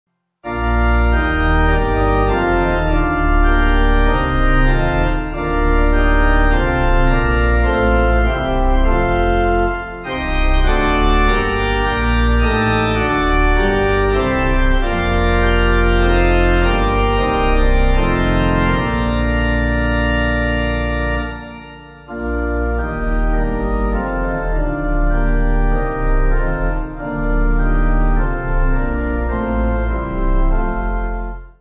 (CM)   6/Bb